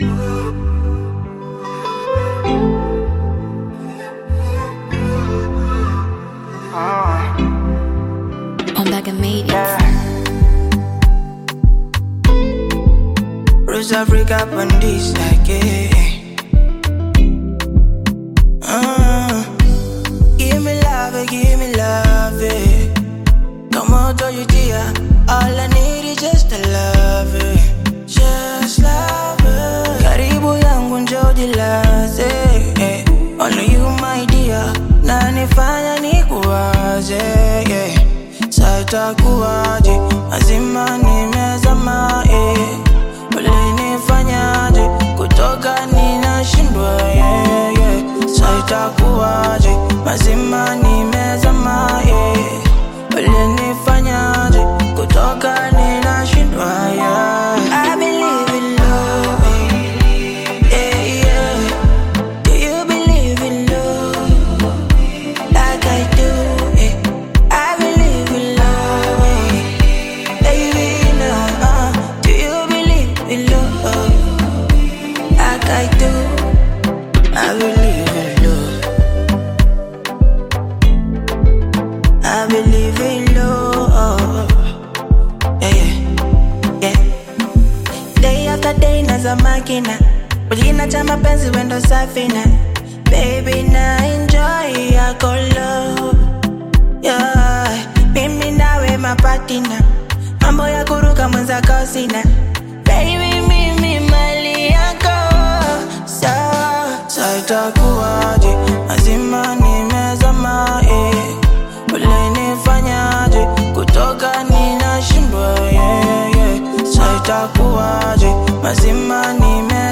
Bongo Flava music track
Bongo Flava You may also like